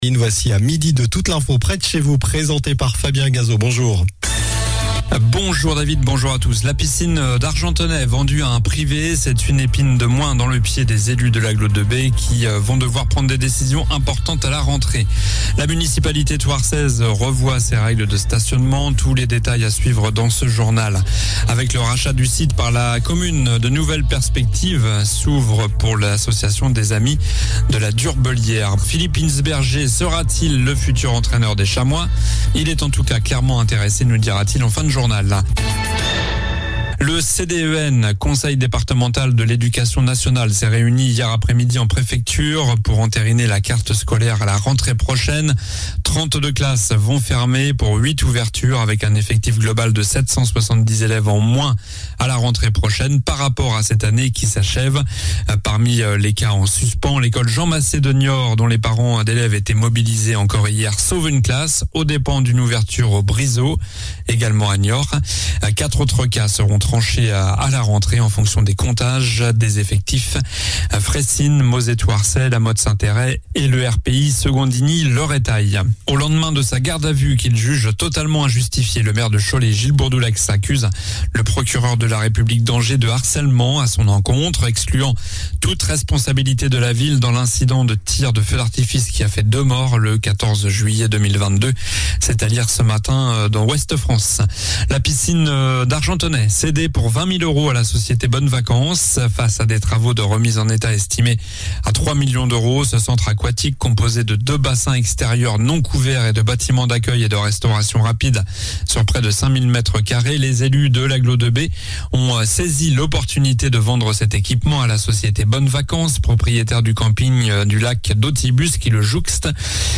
Journal du mercredi 21 juin (midi)